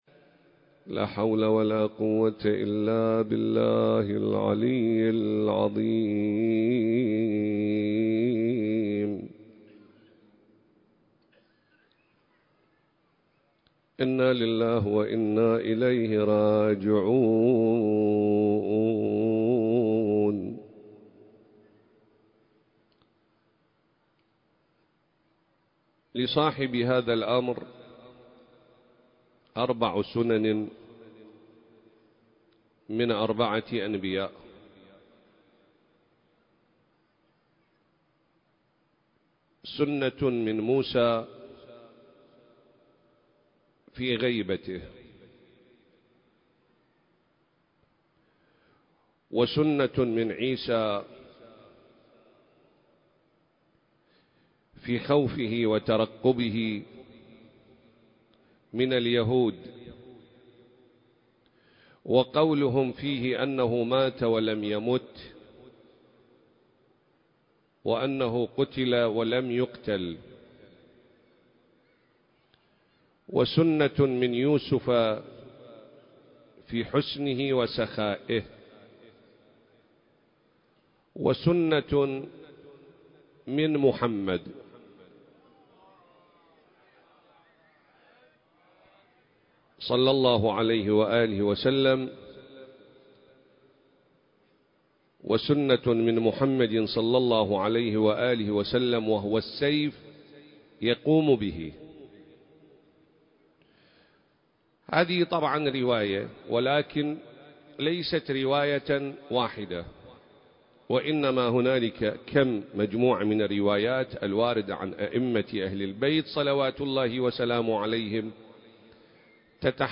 سلسلة: تشابه سنن الأنبياء (عليهم السلام) والإمام المهدي (عجّل الله فرجه) (2) المكان: العتبة العسكرية المقدسة التاريخ: 2024